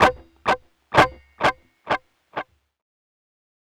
Track 10 - Guitar 02.wav